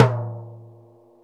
TOM XC.TOM06.wav